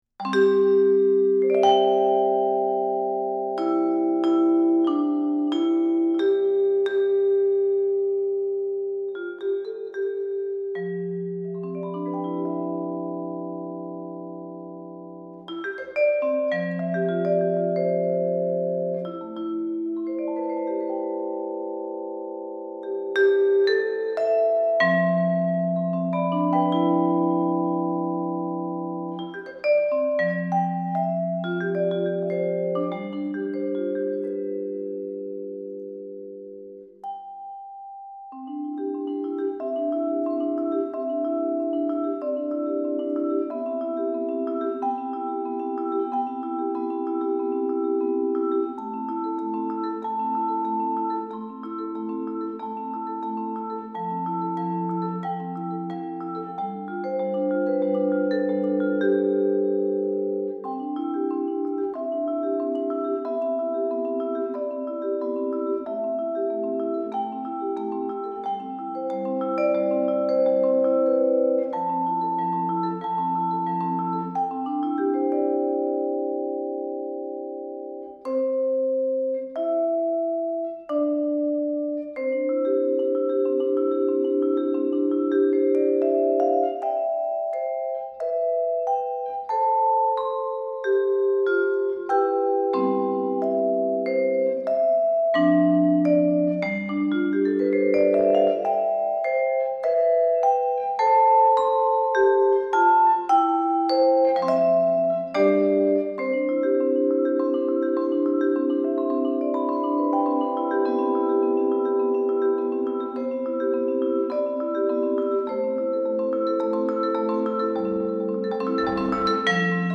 Voicing: Vibraphone Unaccompanied